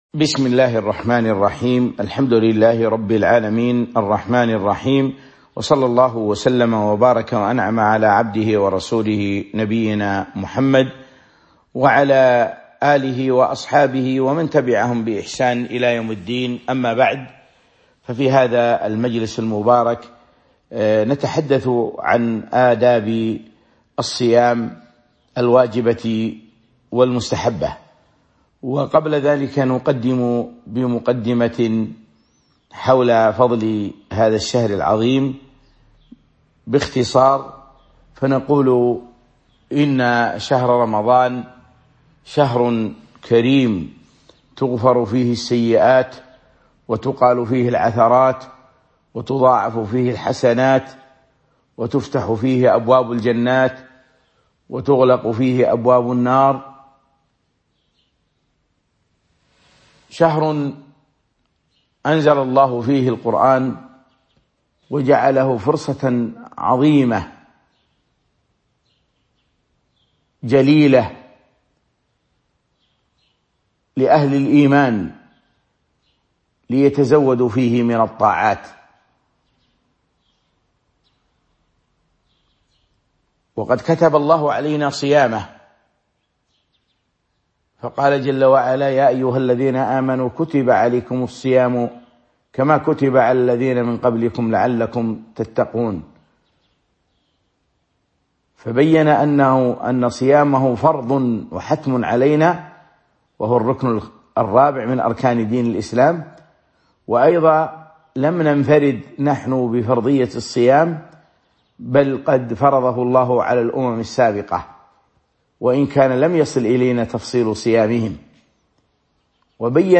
تاريخ النشر ٥ رمضان ١٤٤٢ هـ المكان: المسجد النبوي الشيخ